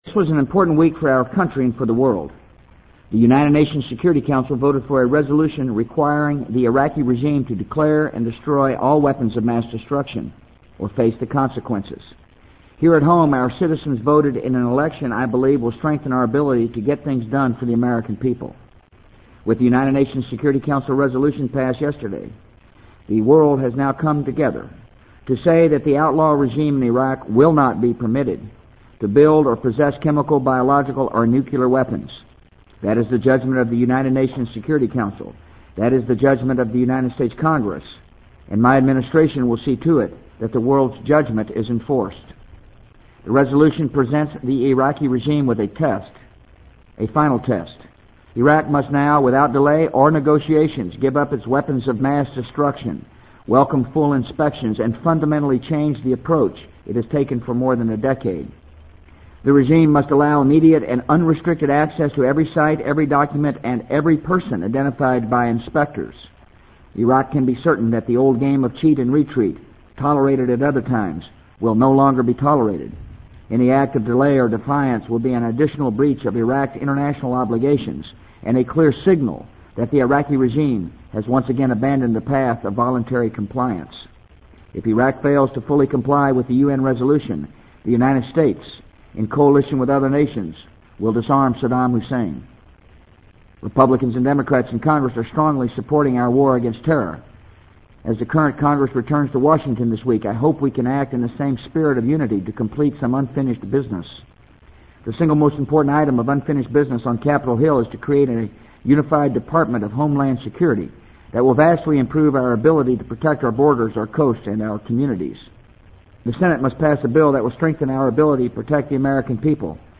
【美国总统George W. Bush电台演讲】2002-11-09 听力文件下载—在线英语听力室